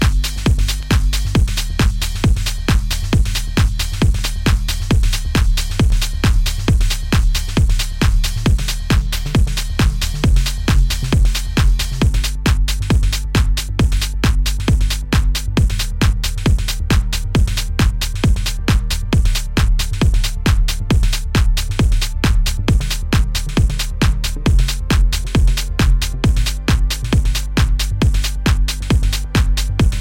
Spaced out future funk!